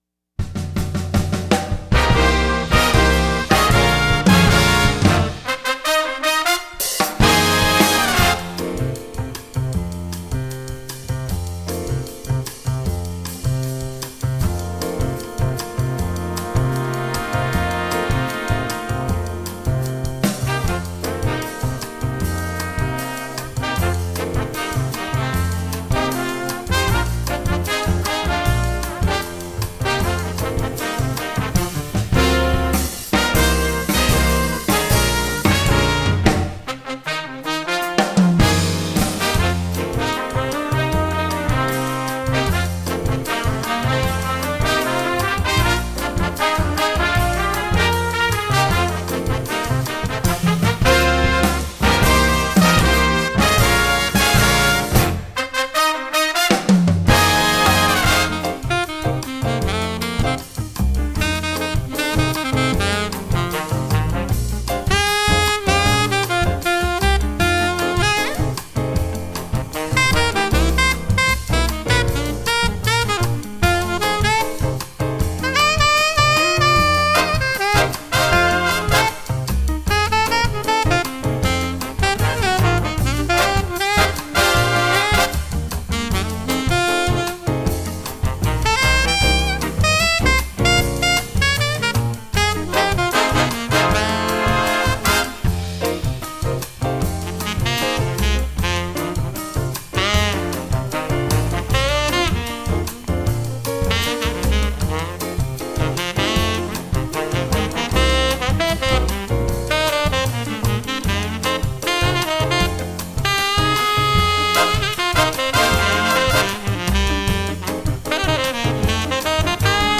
Finally, there will be a sight reading excerpt for all players in medium swing style.